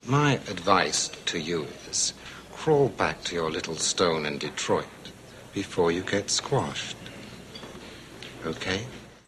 In particular, the widespread and often discussed idea that British or English speech is associated with evil.
Or Steven Berkoff’s smuggling art dealer in Beverly Hills Cop: